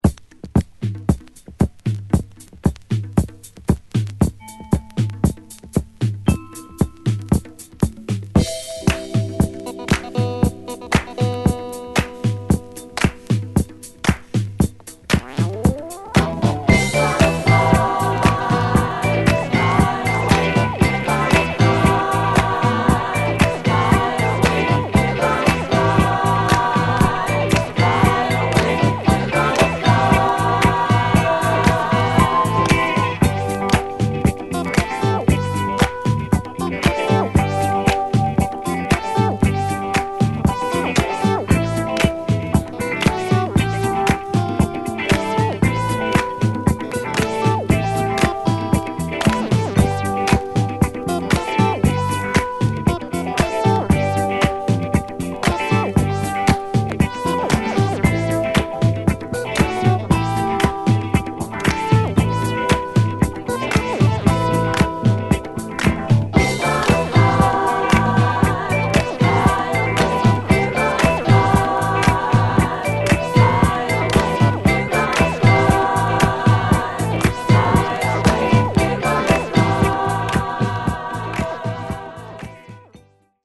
・ 45's SOUL / FUNK / DISCO / JAZZ / ROCK